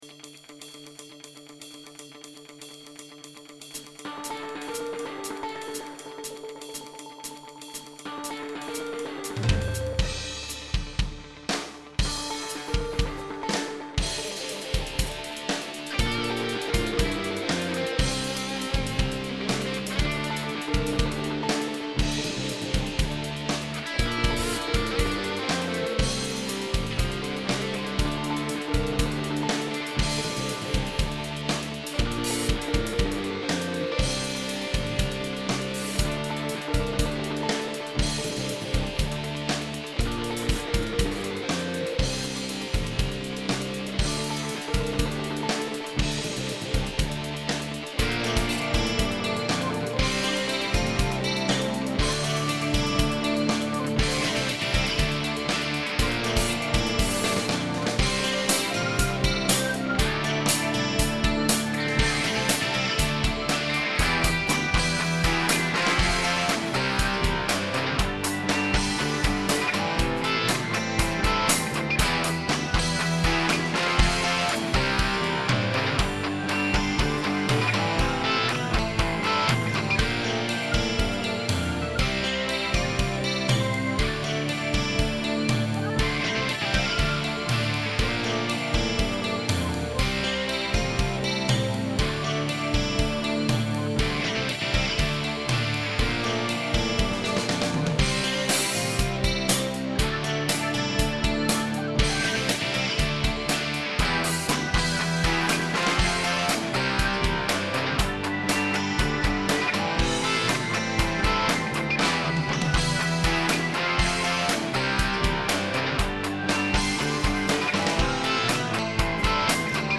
Guitar & Keys
Drums